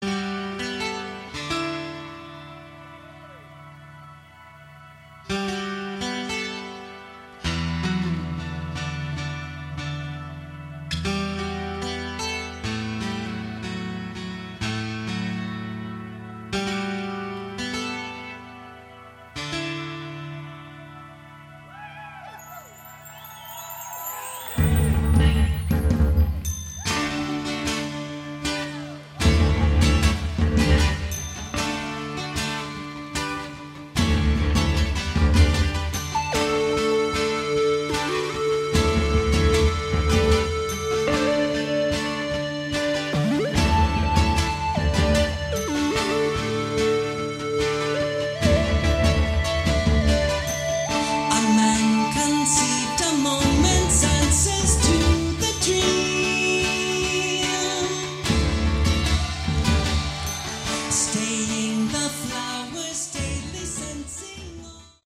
Category: Prog Rock
vocals
guitars
bass
drums
keyboards